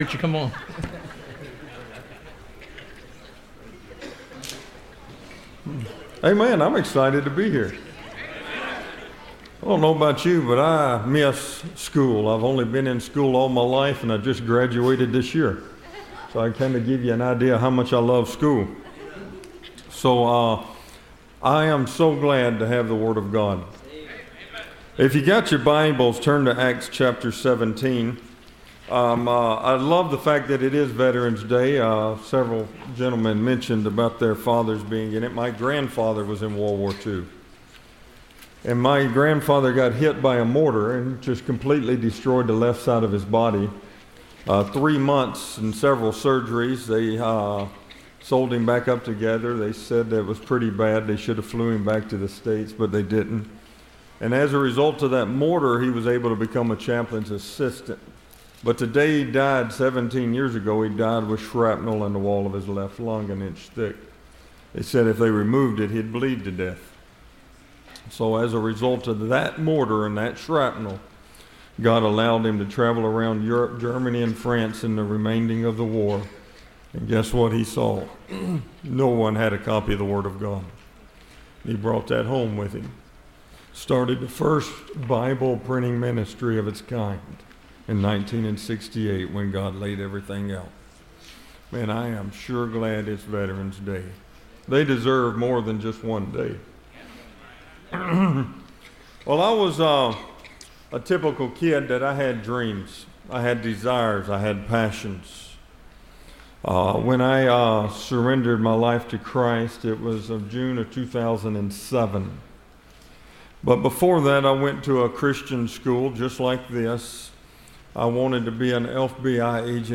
Service Type: Missions Conference